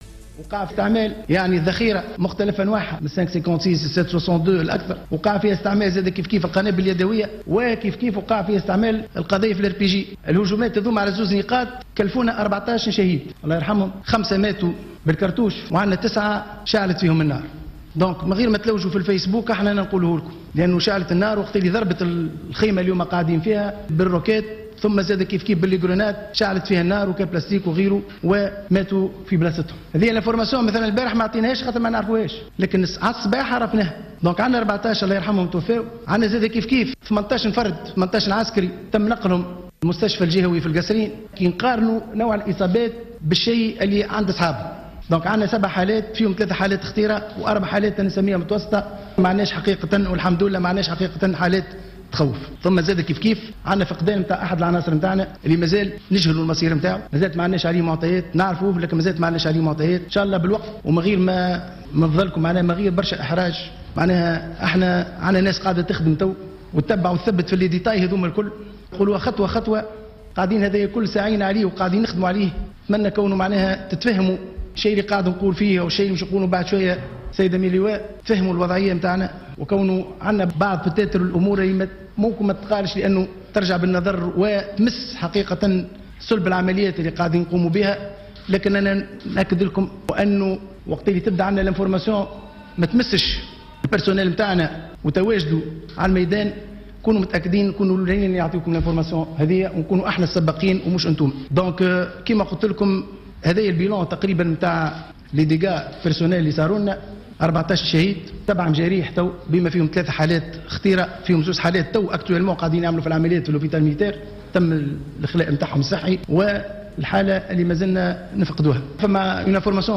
صرح العميد سهيل الشمنقي المسؤول عن العمليات في اركان البر منذ قليل و خلال ندوة صحفية بمقر رئاسة الحكومة بأن الهجوم استهدف نقطتين مما اسفر عن إستشهاد 9 جنود اشتعلت فيهم النيران بعد قصف خيمتهم بقذائف الاربي جي و 5 اخرين توفوا بالرصاص